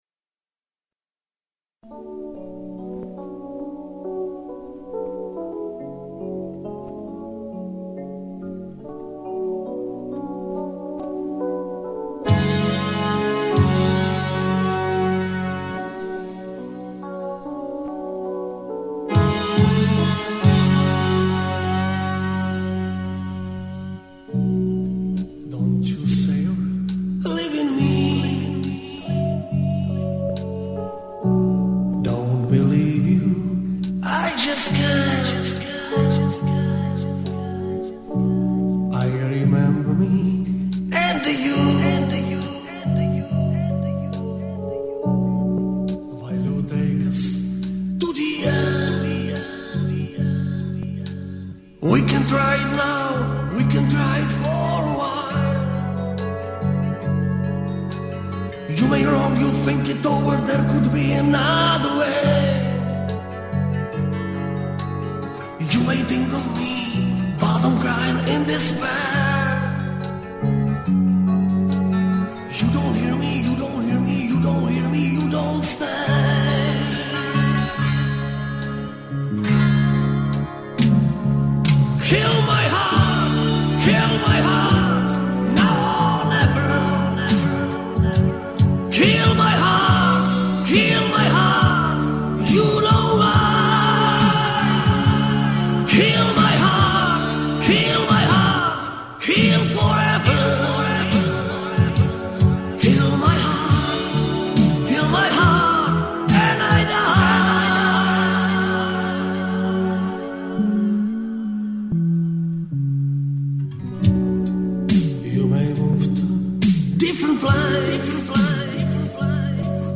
Так было оцифровано моим приятелем с винила.